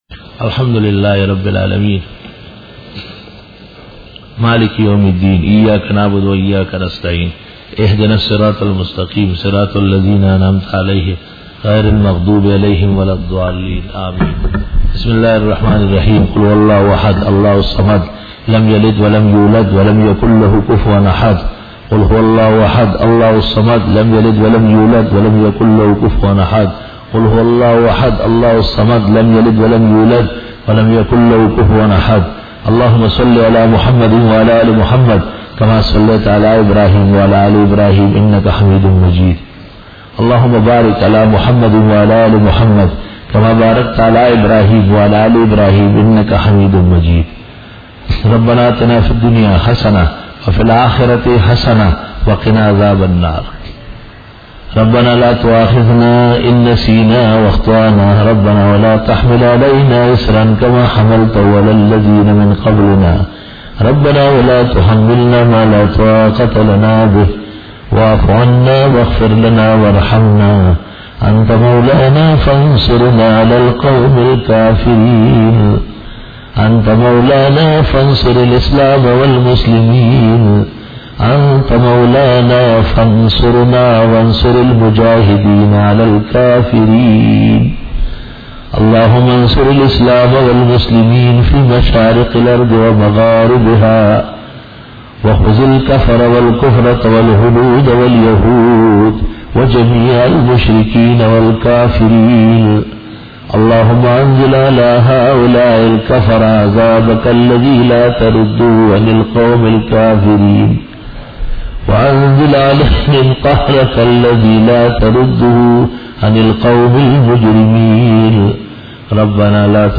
دعا Bayan